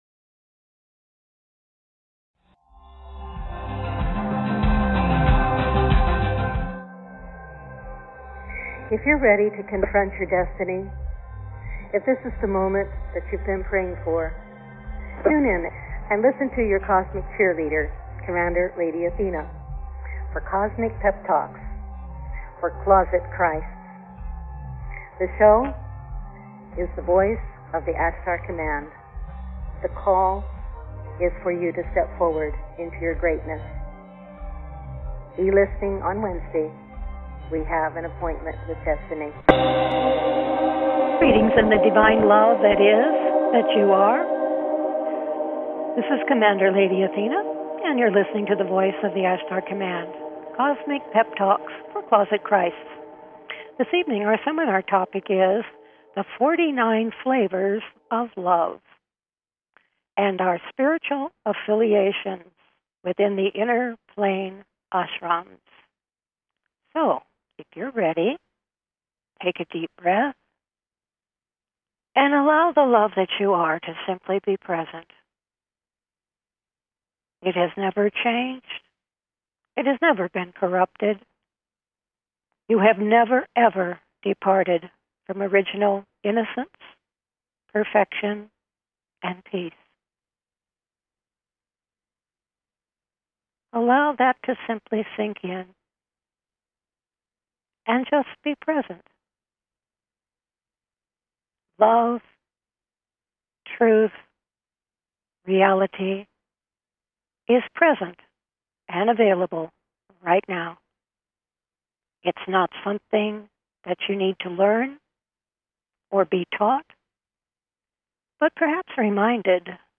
Talk Show
Various experiential processes, meditations and teachings evoke your Divine knowing and Identity, drawing you into deeper communion with the All That Is.